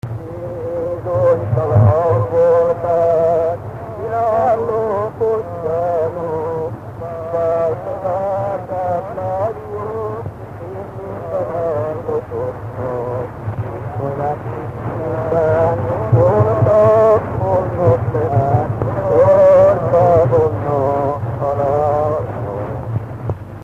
Dunántúl - Sopron vm. - Vitnyéd
Stílus: 4. Sirató stílusú dallamok
Kadencia: 4 (1) b3 1